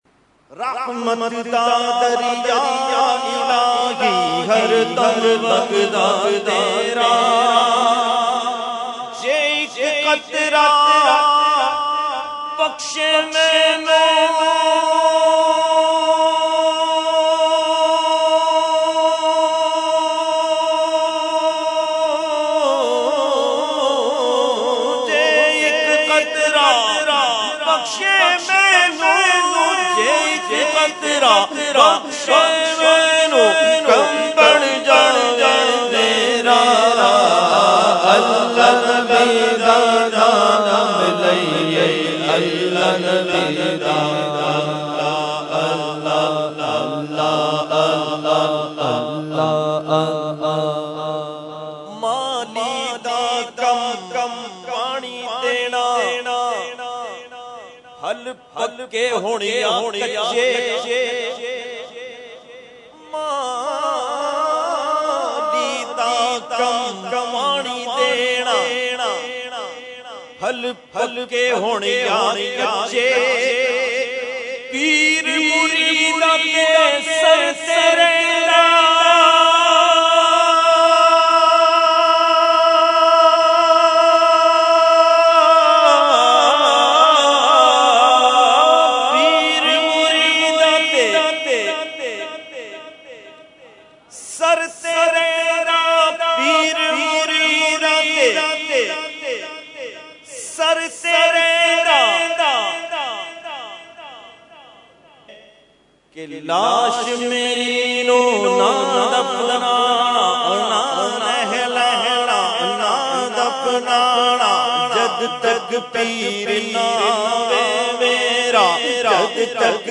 Category : Naat | Language : Punjabi